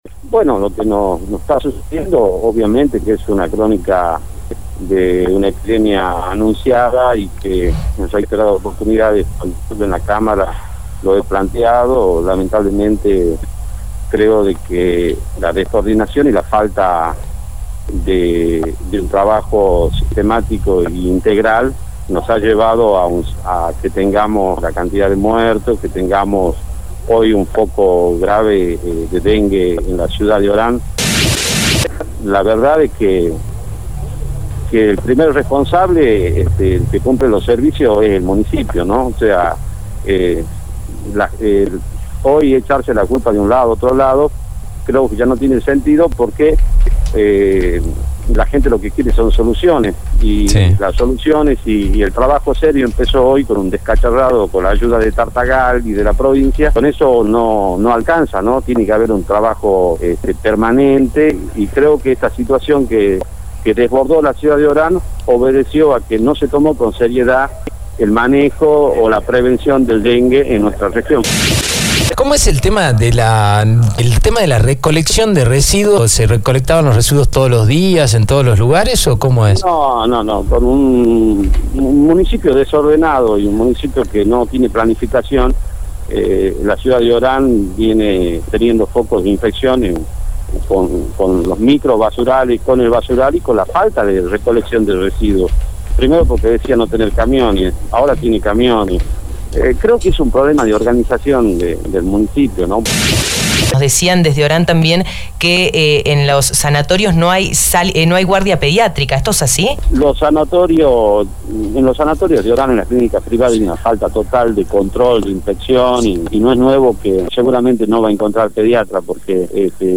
El Diputado mandato cumplido Antonio Hucena dejó sus apreciaciones sobre el dengue en una entrevista con Radio Dinamo.